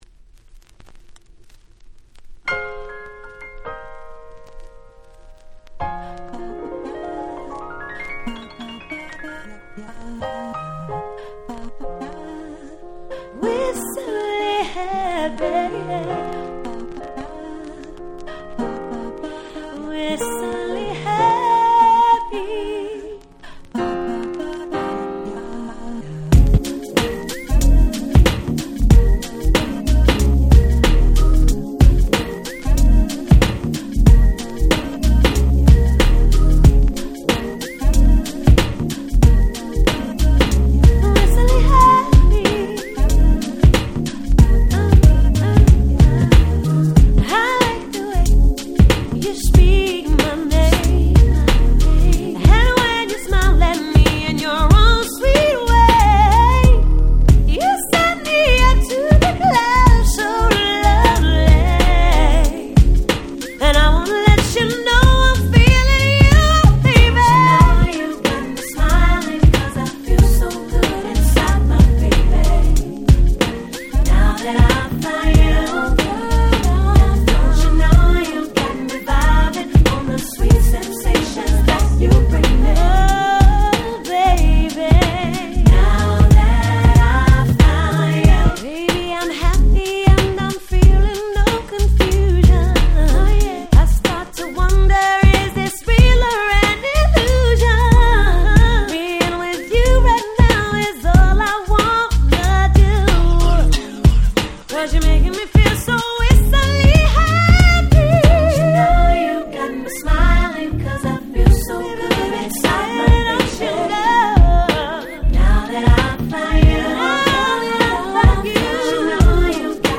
97' Super Nice R&B !!
知らないで聴いたら「誰が歌ってるUK Soul？」って感じです！